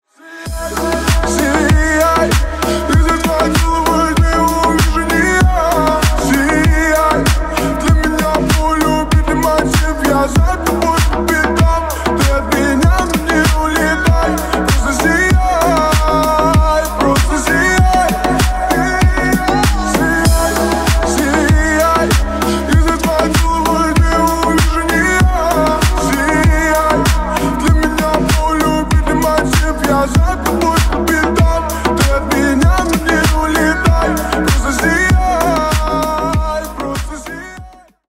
• Качество: 320 kbps, Stereo
Ремикс
грустные